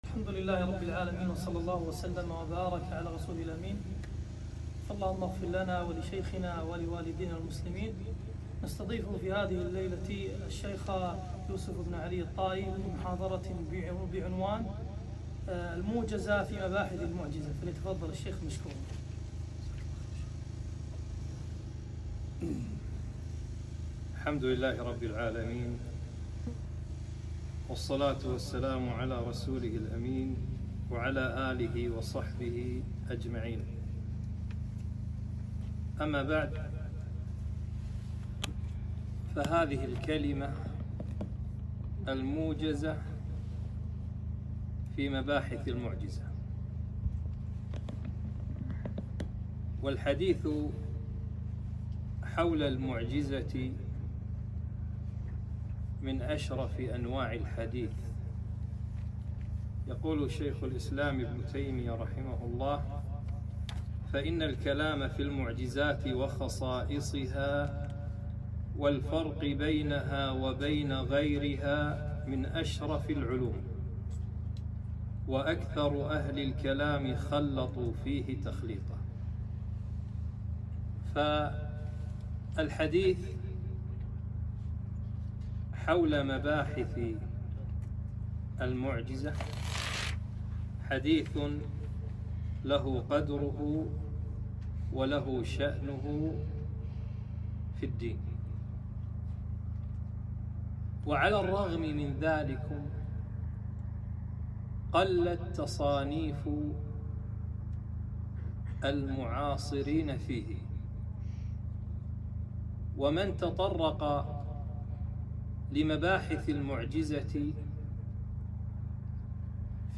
محاضرة - الموجزة في مباحث المعجزة